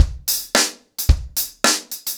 DaveAndMe-110BPM.19.wav